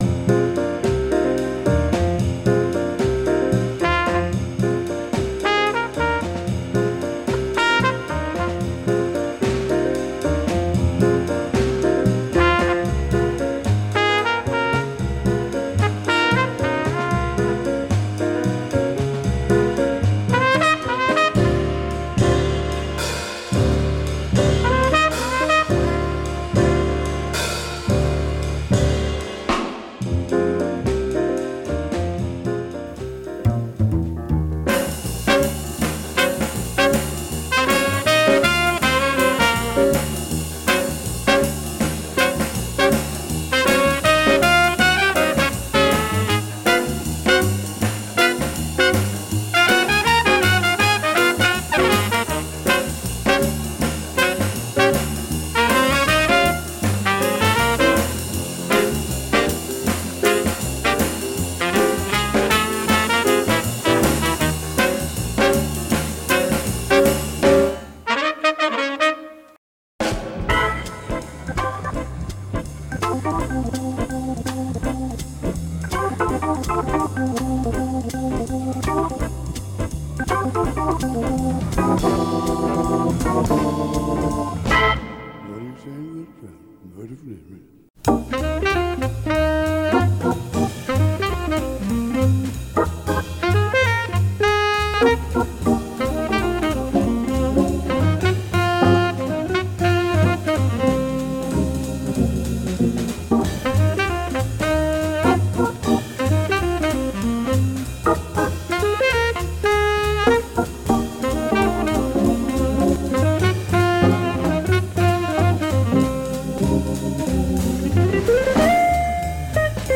Zowel vocaal als instrumentaal. Altijd sfeerverhogend.